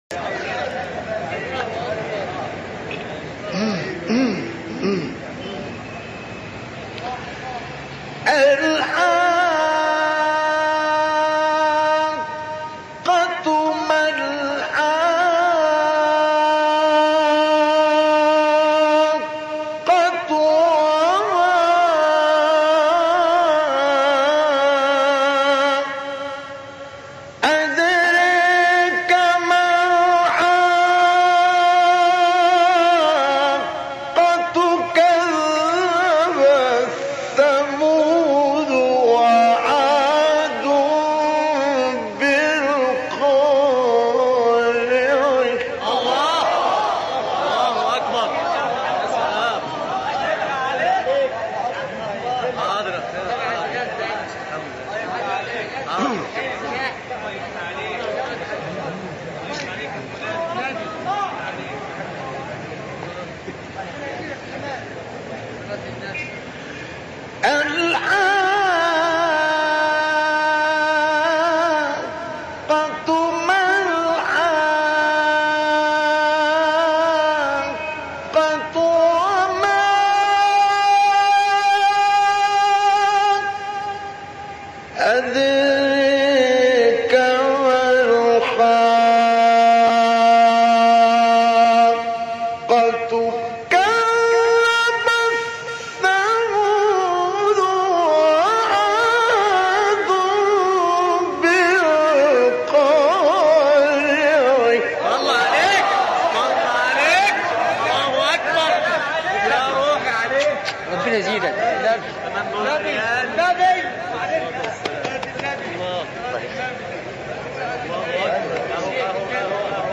تلاوت شاهکار آیه 1-24 سوره حاقه و 26-33 نازعات مصطفی اسماعیل | نغمات قرآن | دانلود تلاوت قرآن